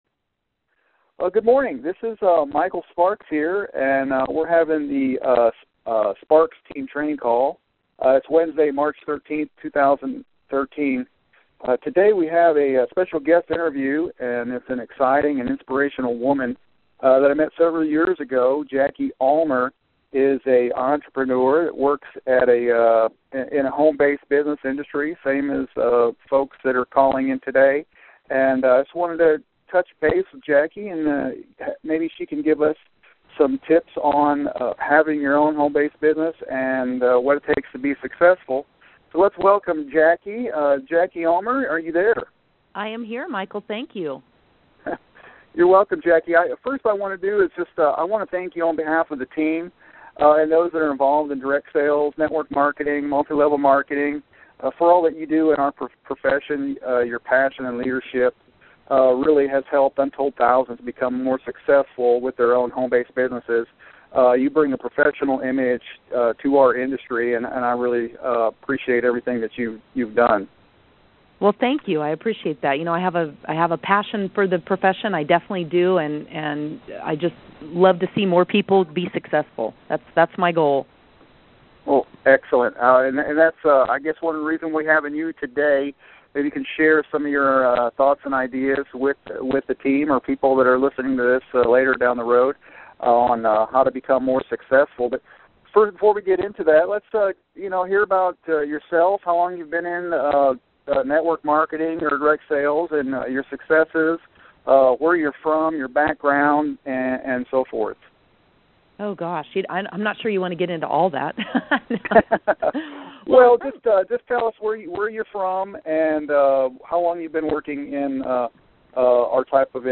Sparks Team Interview